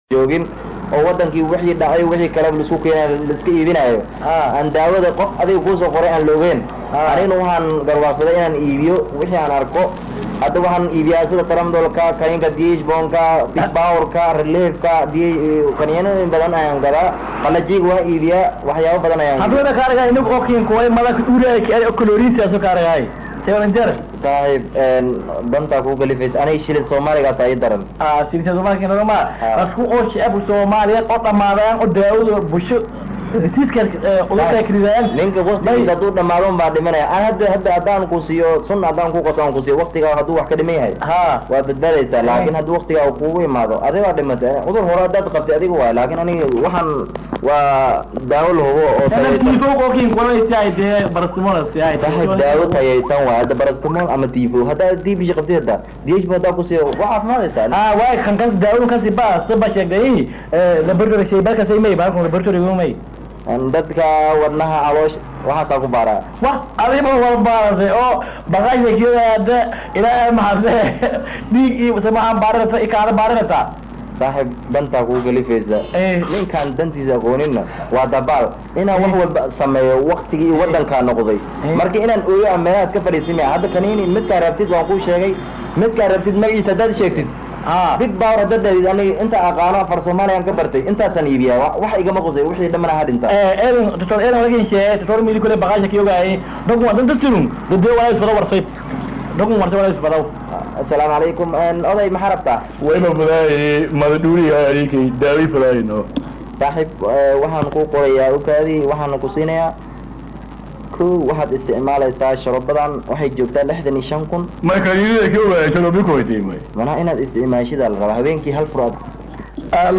Halkan Ka Dhageyso: Riwaayad Ka Hadleysa Dhibaatooyinka Farmashiyada Baydhabo, oo Dad aan Ehel u ahayn ka iibinyo Daawooyin.